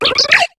Cri de Pachirisu dans Pokémon HOME.